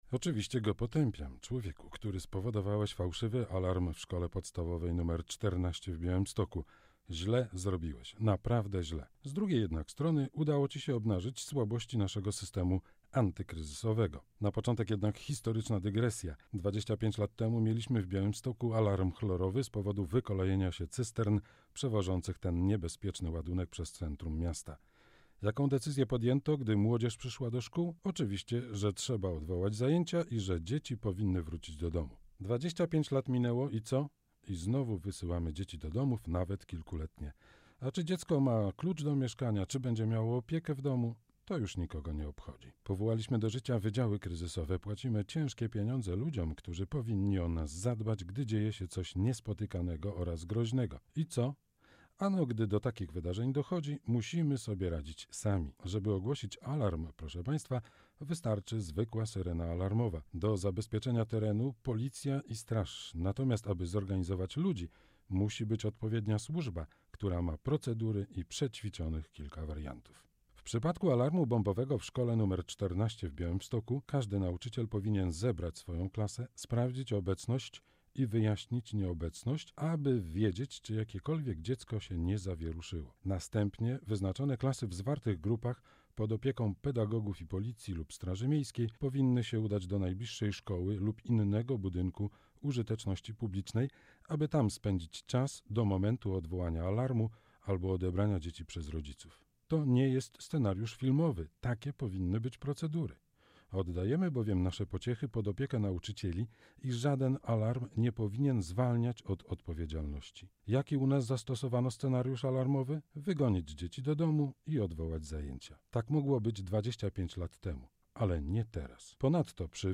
Fałszywy alarm - komentarz